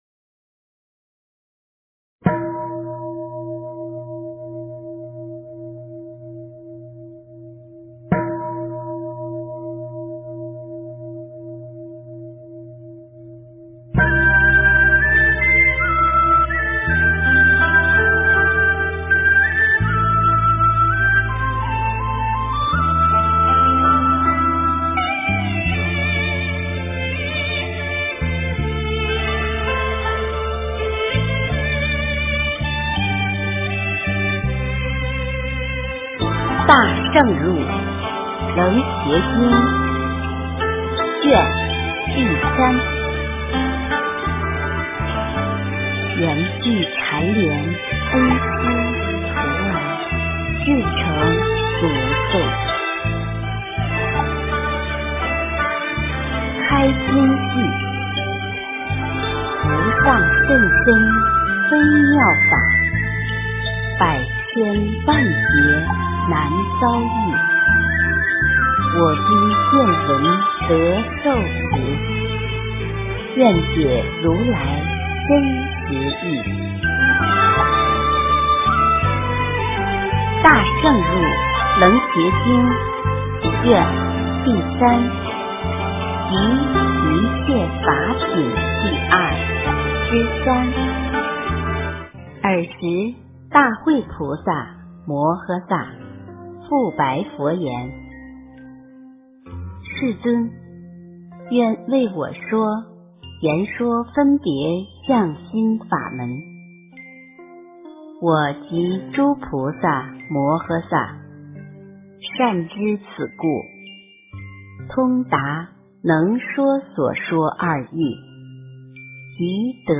《楞伽经》第三卷上 - 诵经 - 云佛论坛